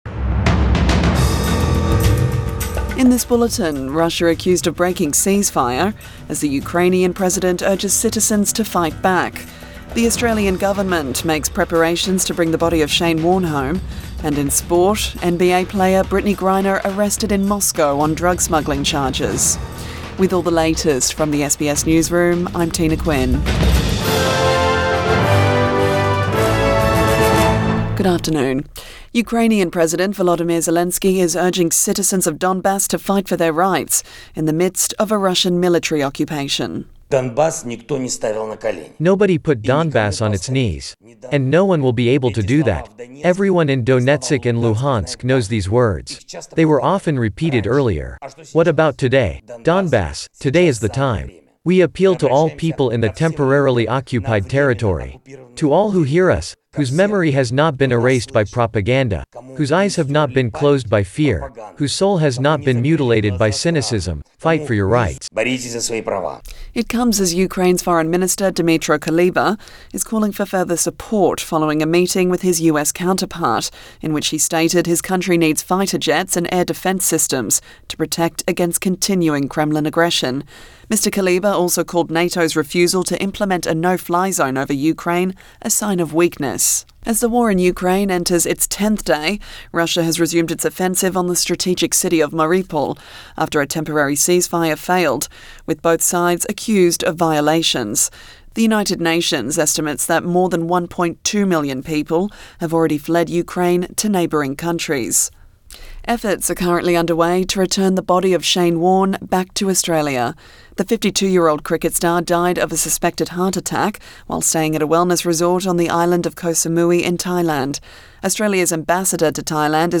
Midday bulletin 6 March 2022